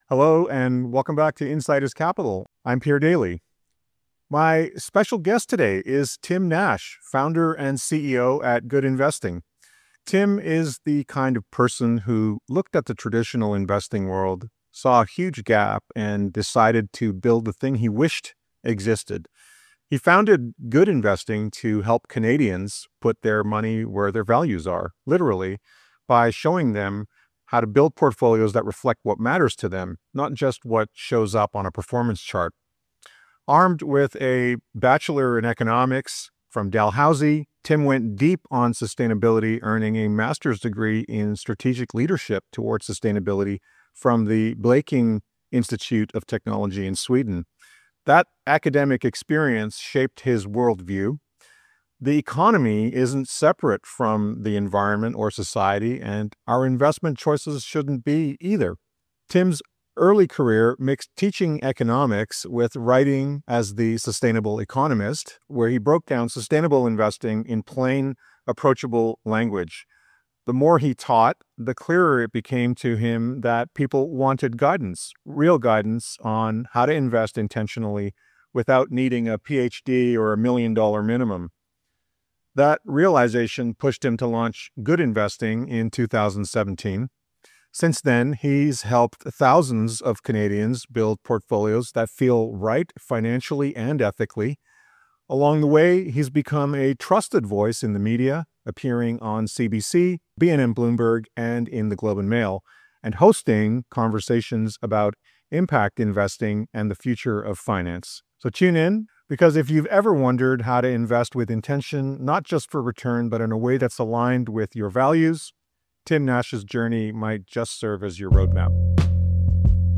In this wide-ranging and human conversation